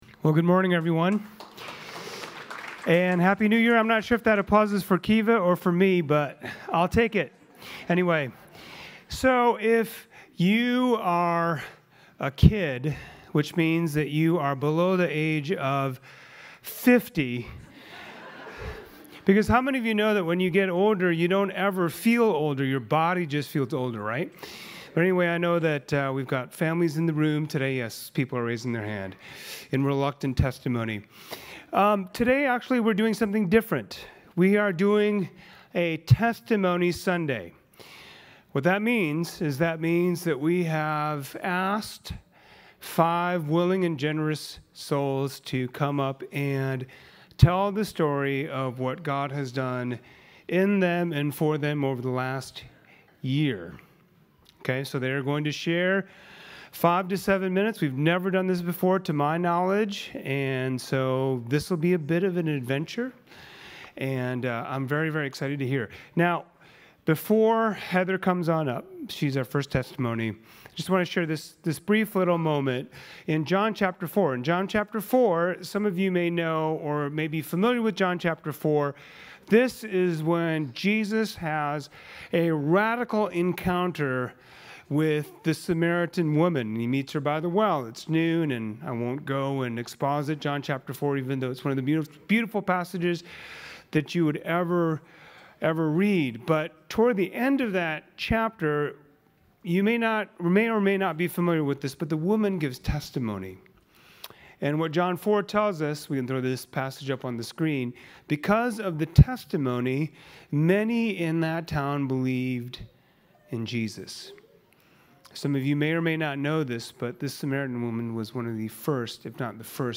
On the last Sunday of the year, we hear testimonies from members of our congregation about what God has been doing in their lives over the past year.